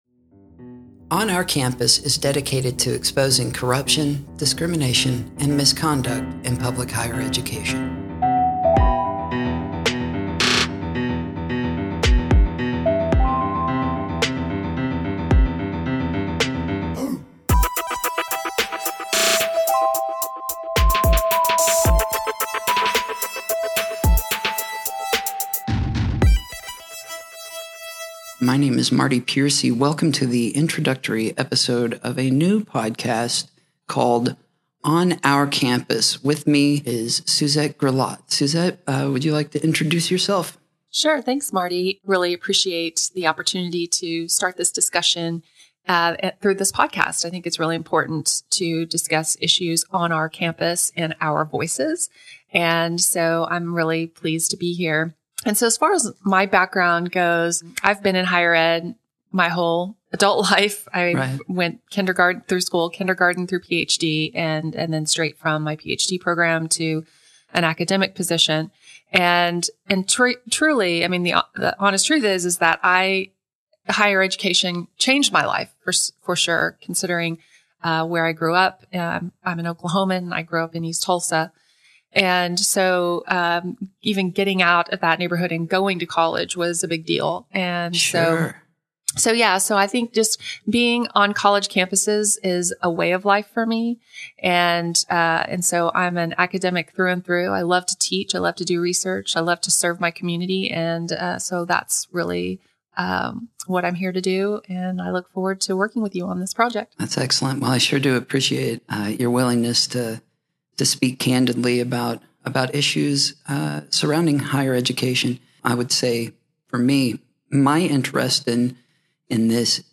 Hosts